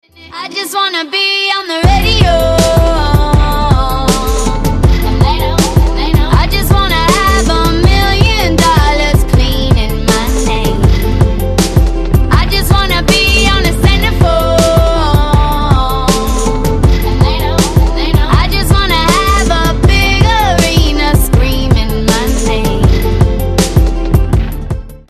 красивые
женский вокал
RnB
alternative